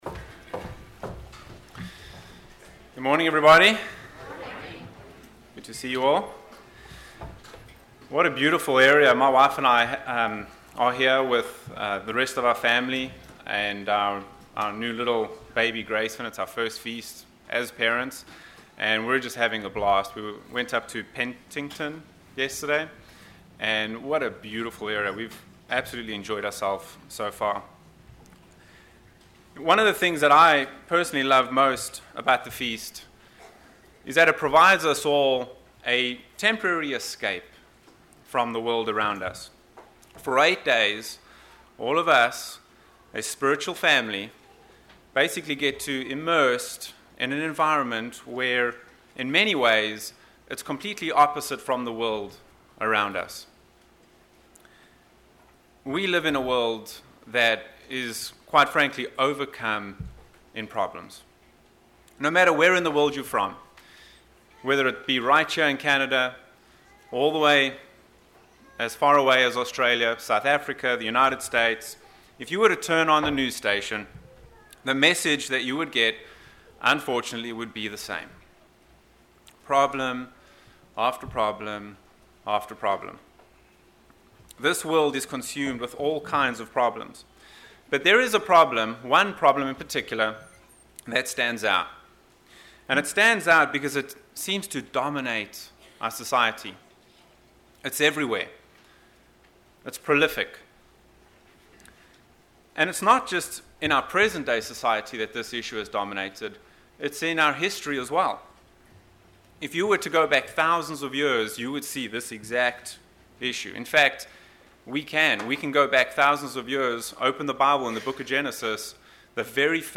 This sermon was given at the Osoyoos Lake, British Columbia 2018 Feast site.